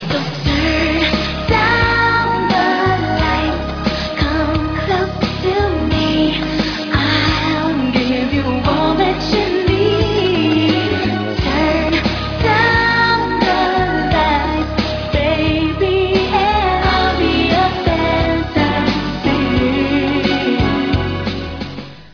Executive producer and background vocals